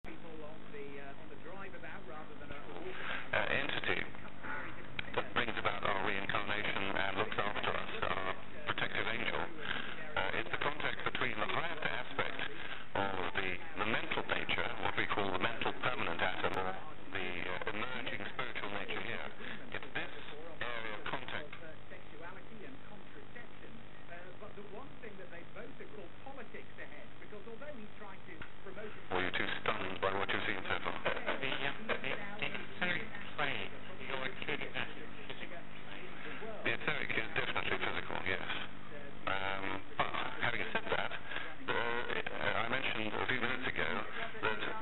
So I stuck one of those microphones that pick up vibrations from a flat surface on the screen (as seen on TV – below).
Â The transmitter was placed in another room: so the signal had to go through a wall before getting to the TV.
tv and transmitter recording
I switched the transmitter on and off as seen on the diagram below.
REC017switch-on-switch-off-tv-and-transmit2.mp3